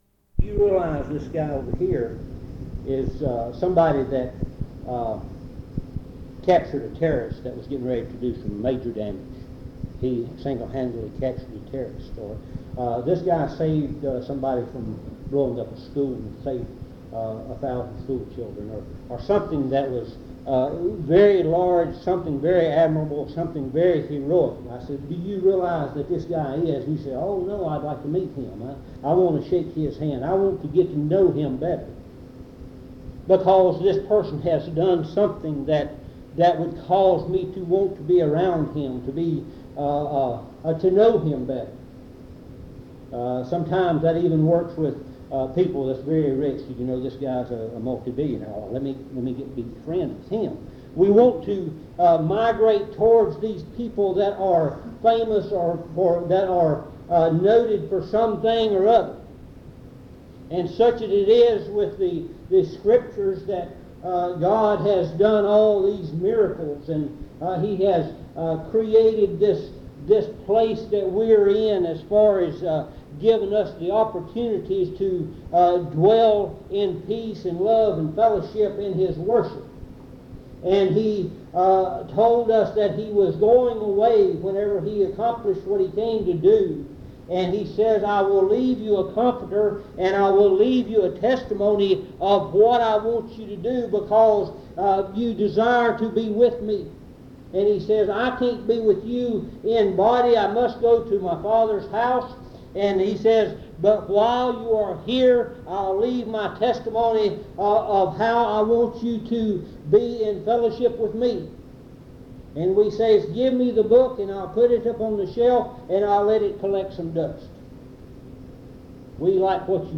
Message
at Monticello Primitive Baptist Church